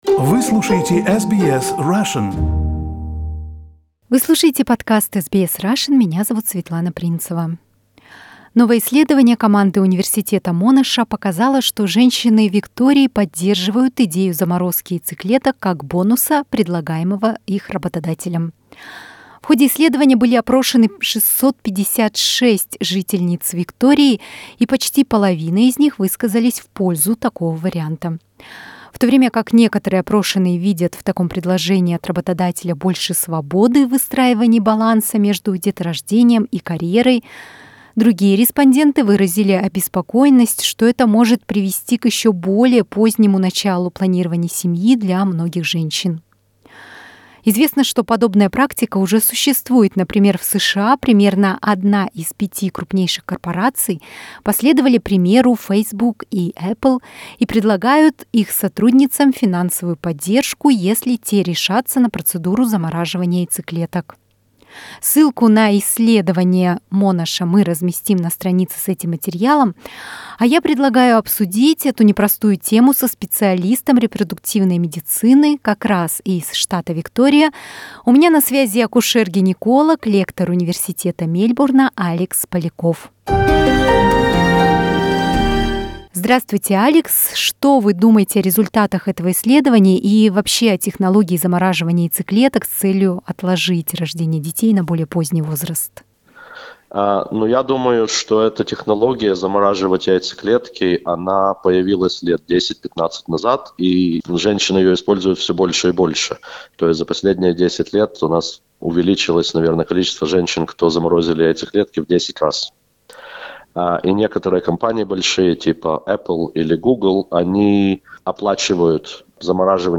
Мы обсудили эту непростую тему со специалистом репродуктивной медицины из Виктории